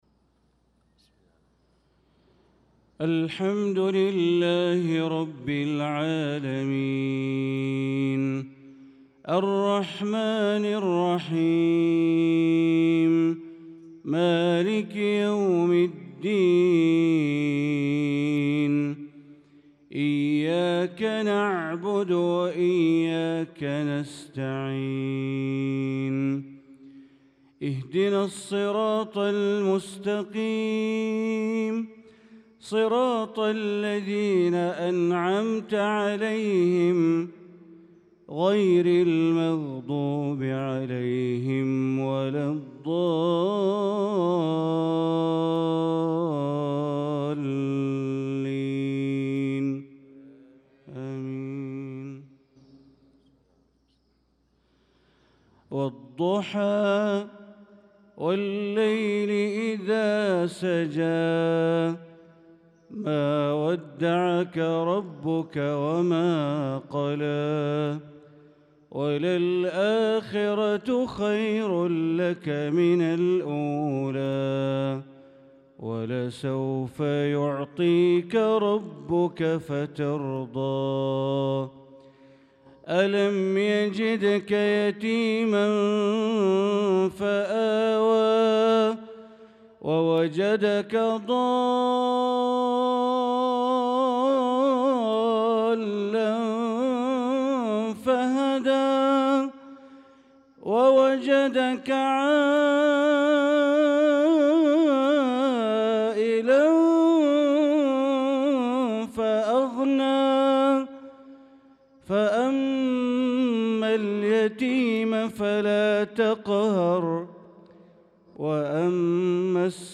صلاة المغرب للقارئ بندر بليلة 16 شوال 1445 هـ
تِلَاوَات الْحَرَمَيْن .